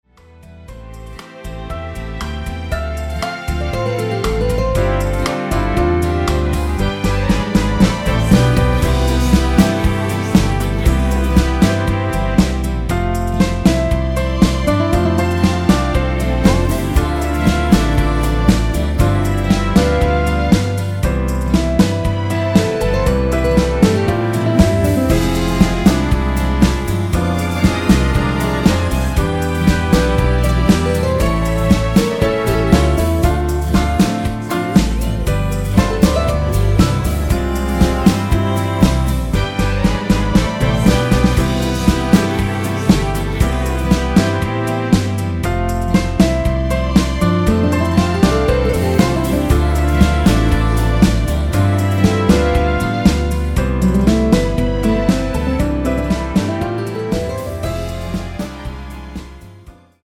코러스 포함된 MR 입니다.(미리듣기 참조).
앞부분30초, 뒷부분30초씩 편집해서 올려 드리고 있습니다.
중간에 음이 끈어지고 다시 나오는 이유는